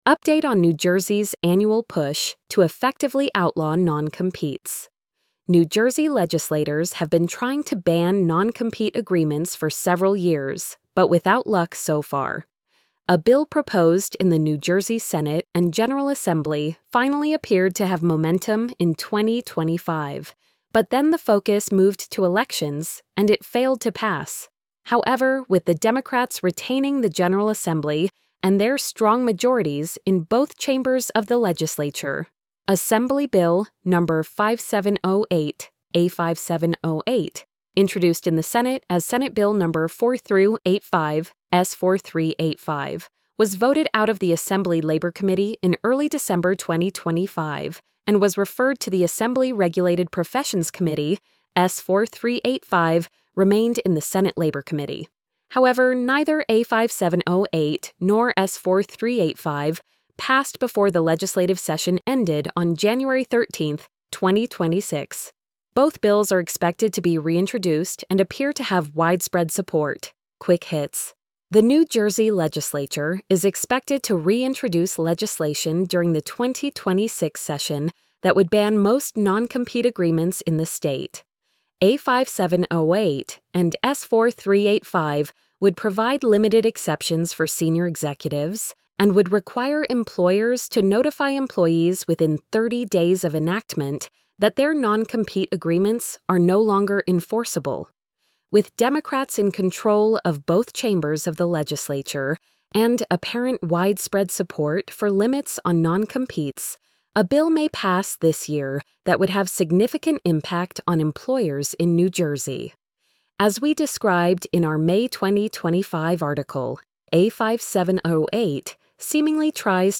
update-on-new-jerseys-annual-push-to-effectively-outlaw-noncompetes-tts.mp3